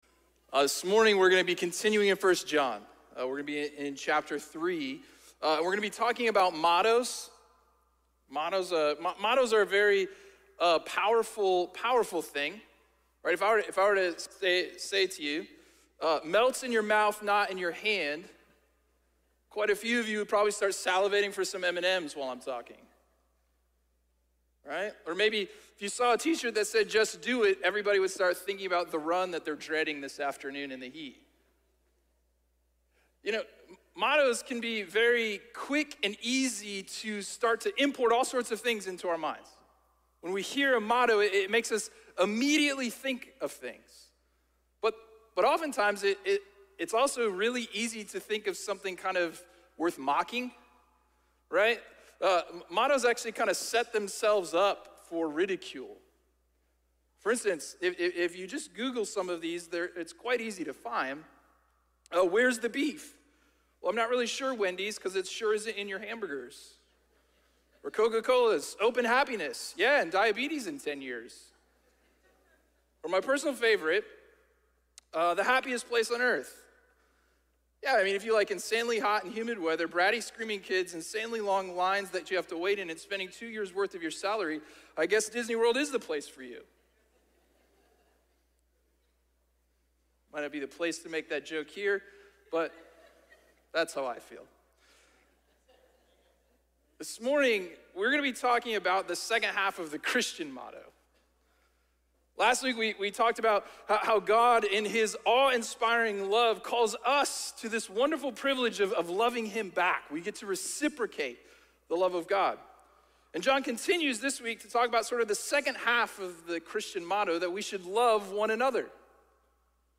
A message from the series "Judges: {Un} Faithful."